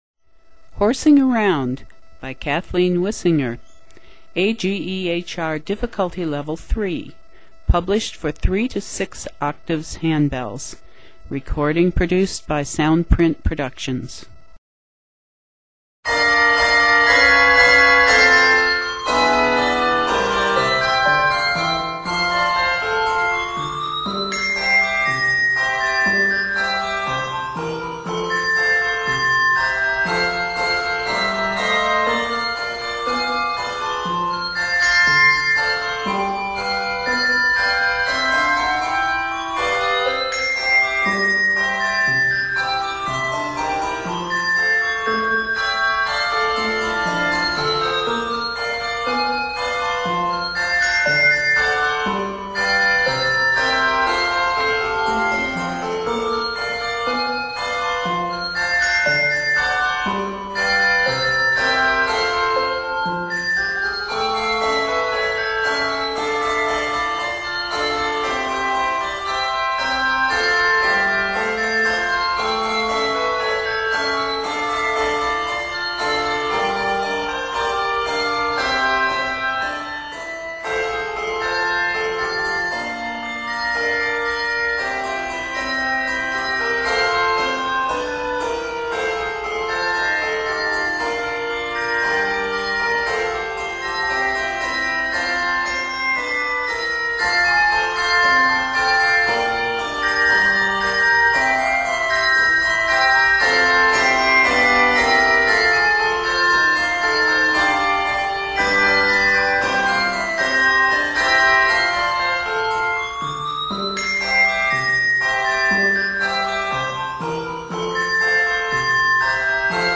Set in the key of F Major, measures total 73.